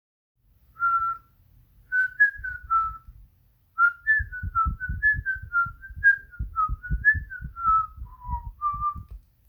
Zo’n dancenummer uit de 90s denk ik
Hahaha nee ik heb dit gefloten maar wel heel vals :joy: